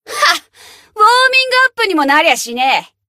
贡献 ） 分类:蔚蓝档案语音 协议:Copyright 您不可以覆盖此文件。
BA_V_Neru_Bunny_Battle_Victory_1.ogg